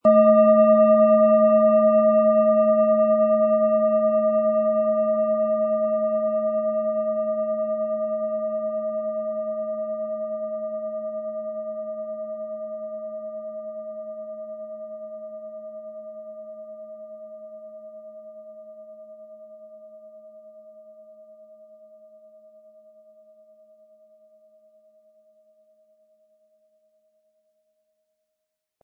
Antike Klangschale mit Neptun-Ton – Für innere Weite, Träume & mediale Tiefe
Im Sound-Player - Jetzt reinhören können Sie den Original-Ton genau dieser Schale anhören.
Den passenden Schlägel erhalten Sie kostenlos mitgeliefert, er lässt die Klangschale harmonisch und wohltuend ertönen.
MaterialBronze